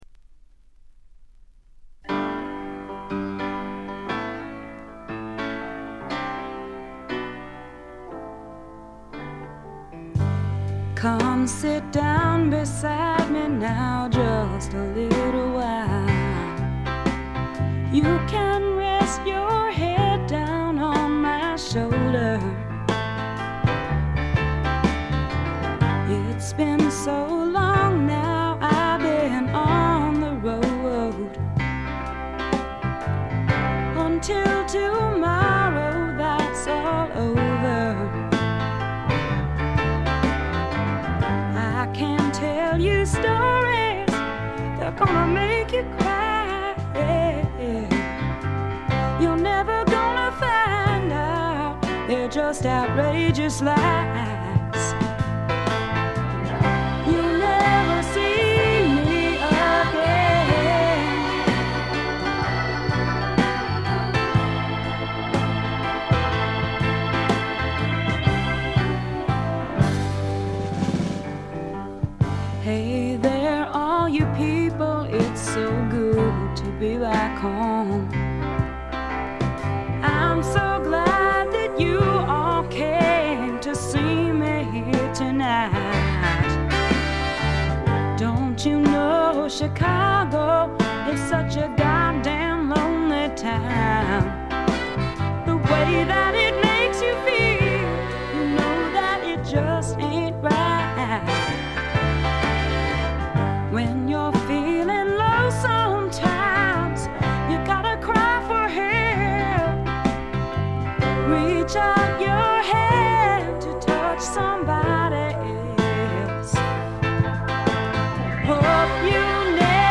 少しコケティッシュで、ソウルフルで、実に魅力的なヴォイスの持ち主。女性スワンプの大傑作です。
試聴曲は現品からの取り込み音源です。